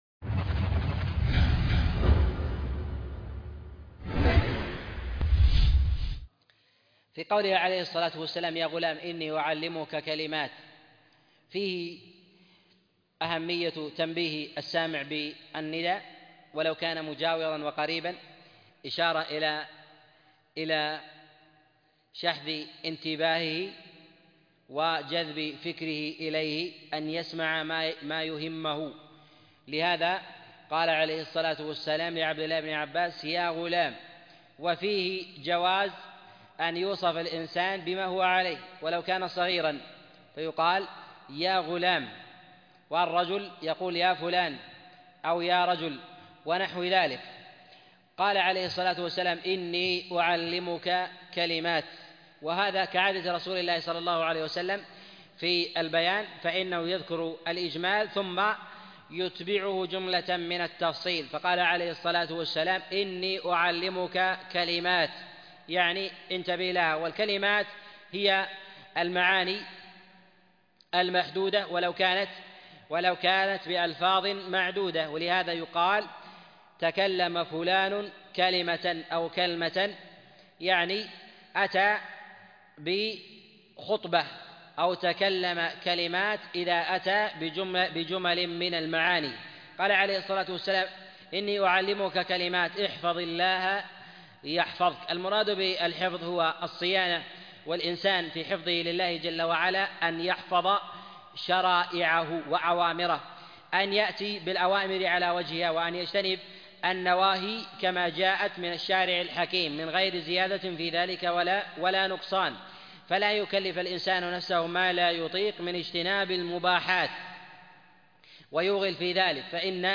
من شرح الأربعين النووية الدرس 19